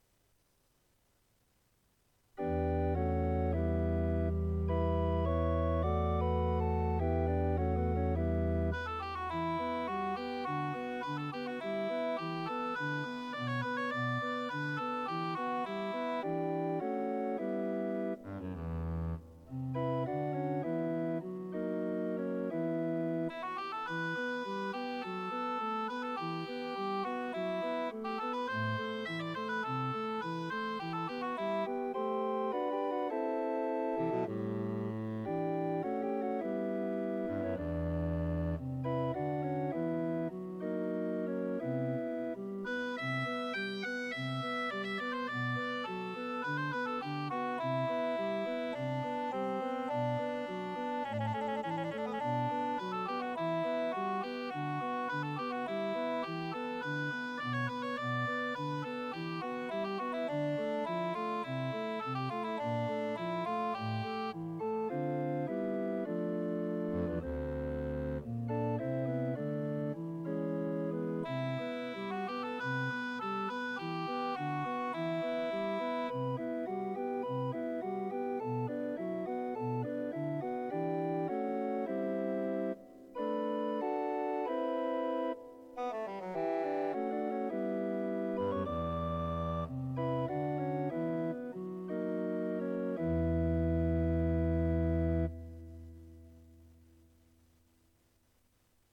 Récit de cromorne
sur 2 claviers, manualiter.